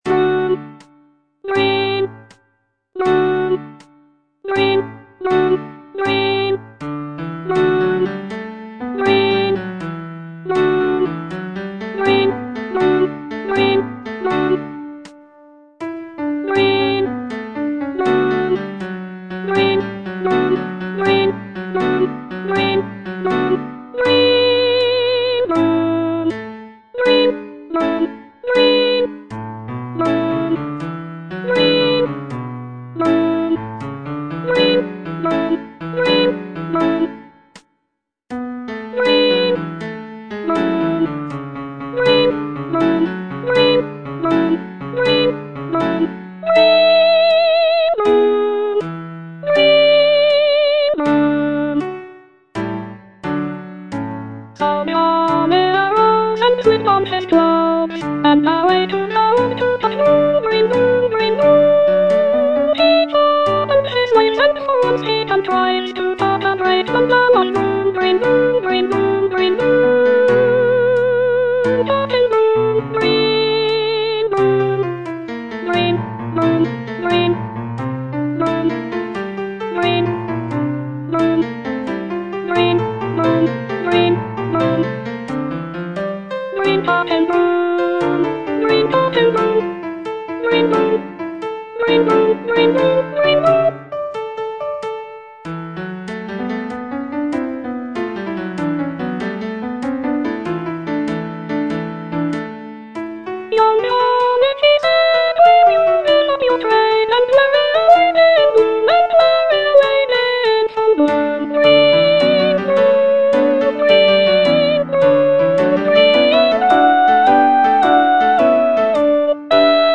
Soprano I (Voice with metronome)